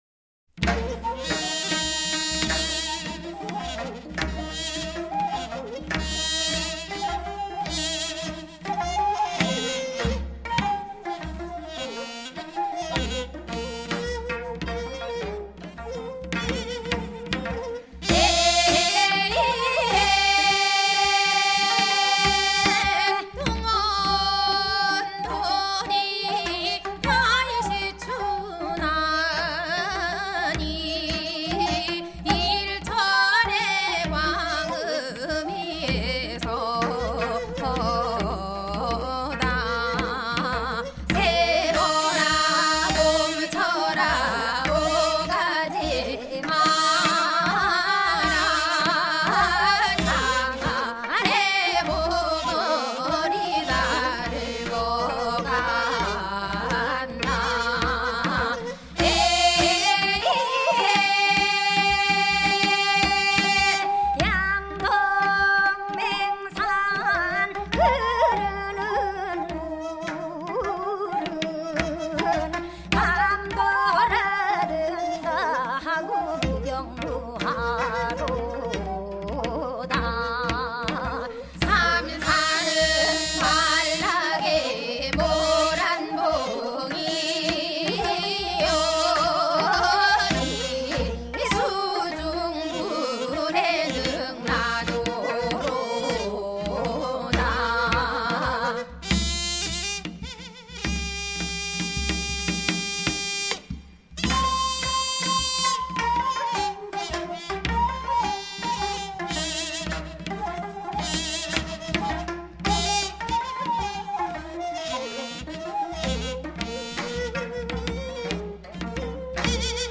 [10/9/2009]原生态系列 朝鲜.韩国原生态民歌 （首尔原生态歌手演唱） 绝对够土！